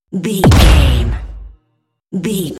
Dramatic drum hit deep
Sound Effects
Atonal
heavy
intense
dark
aggressive